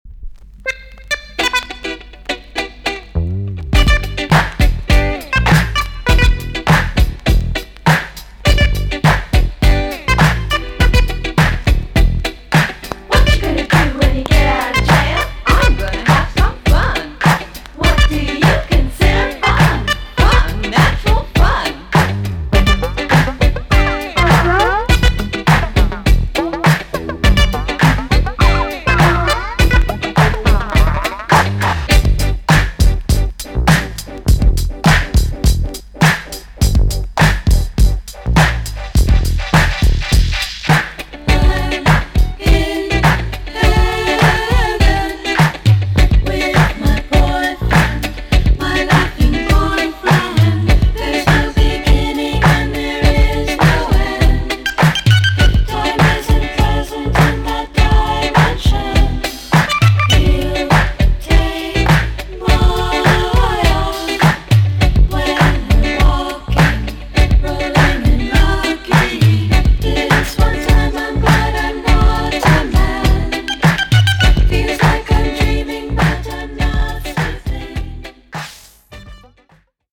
EX-~VG+ 音はキレイです。